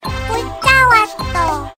알림음 8_문자왔떠.ogg